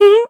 scared-step.ogg